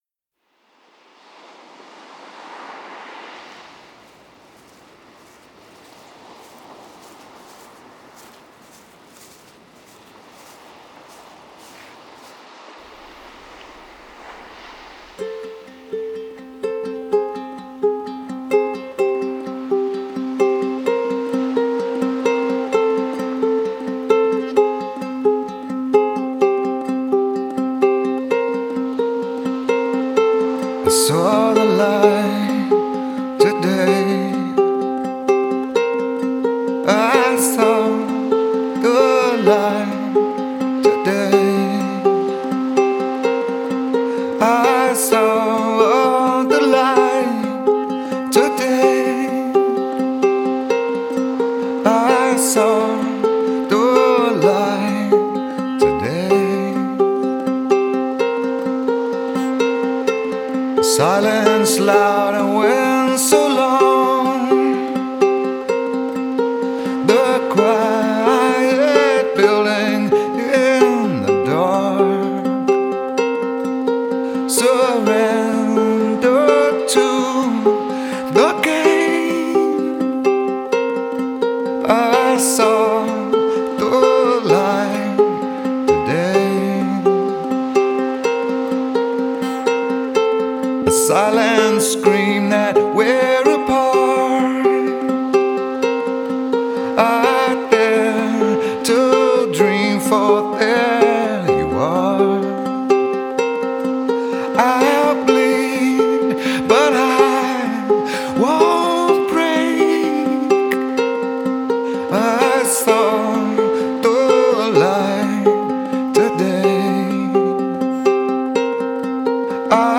released an album of ukulele songs.